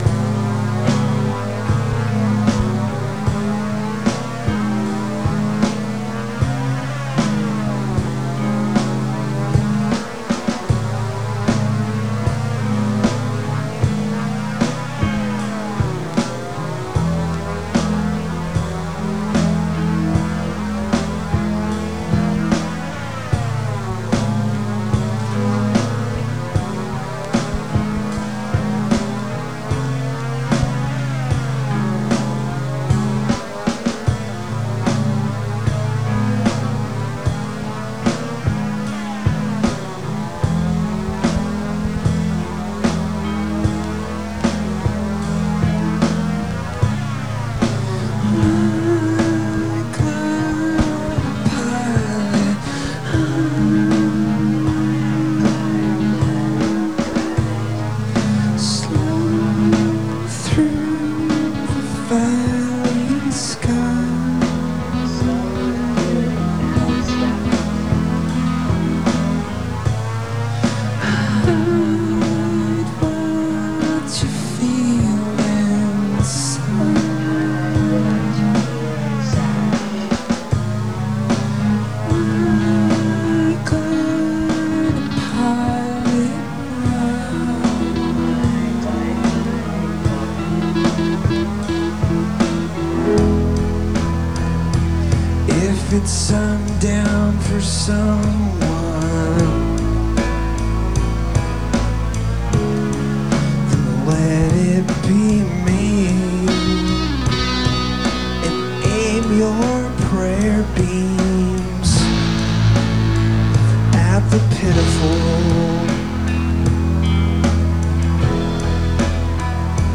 Venue: Mejeriet, Lund, SE